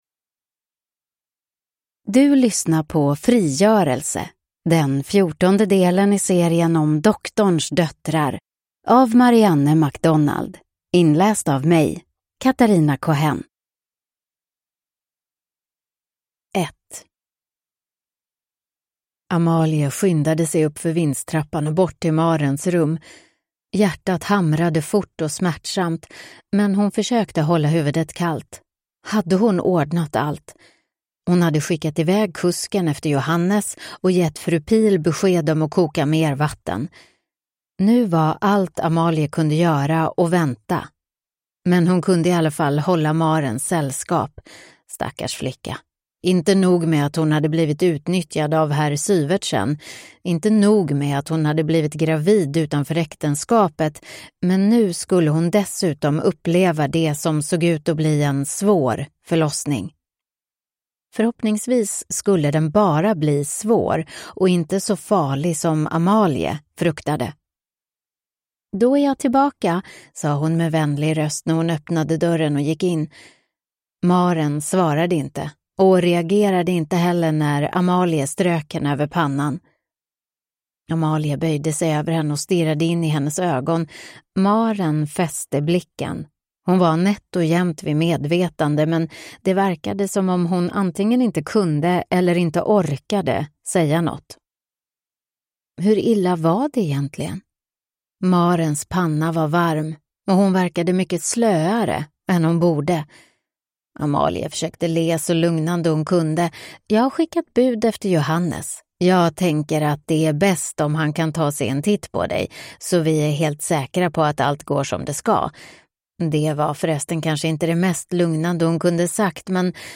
Frigörelse (ljudbok) av Marianne MacDonald | Bokon